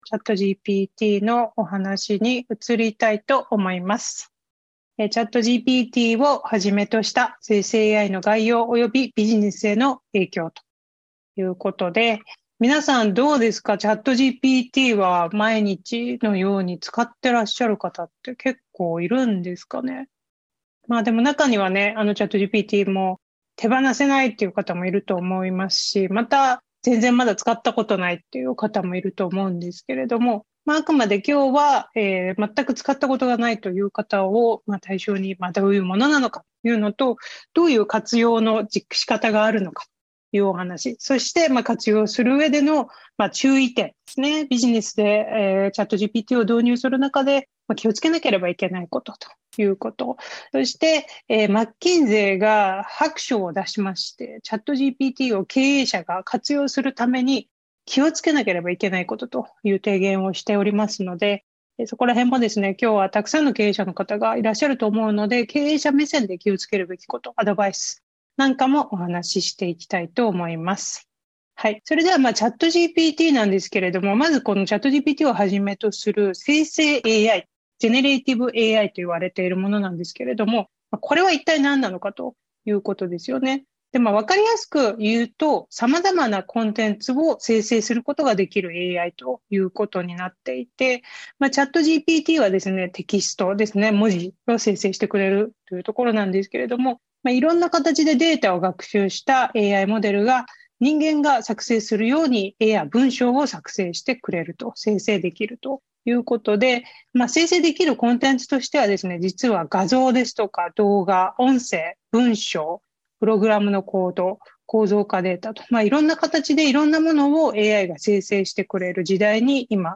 また、オンラインセミナーの収録のため、ノイズがございます。